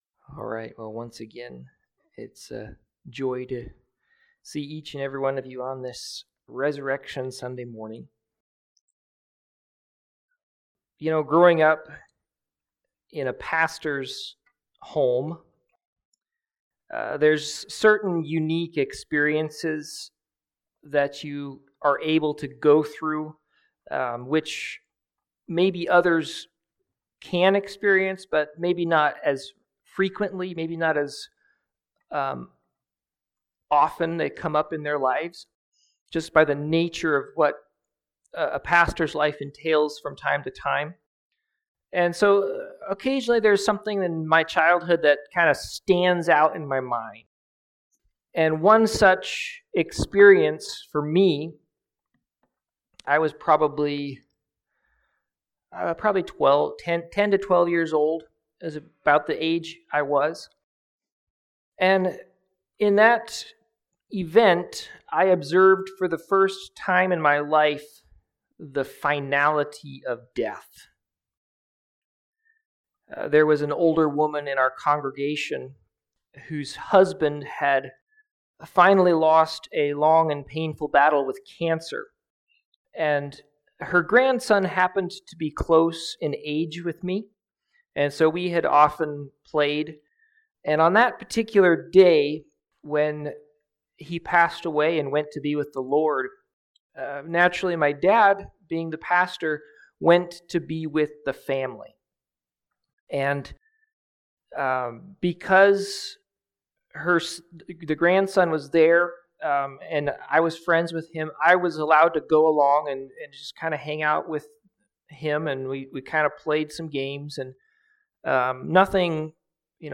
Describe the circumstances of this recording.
HAPPY RESURRECTION SUNDAY!!!!!!